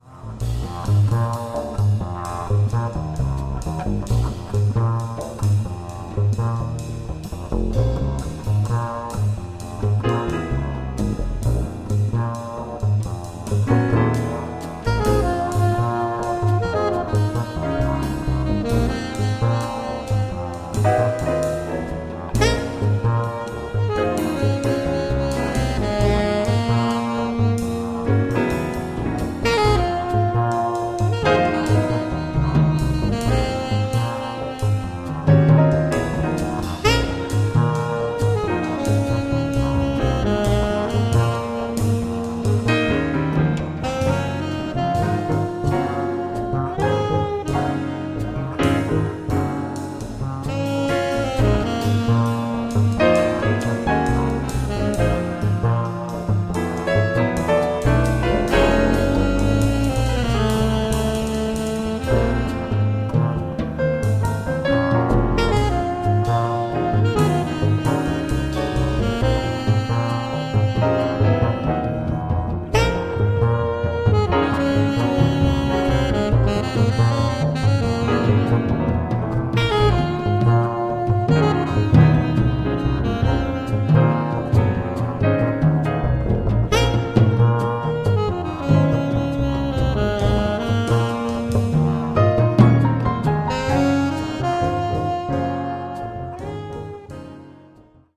Recorded live at the Vancouver Jazz Festival, Canada,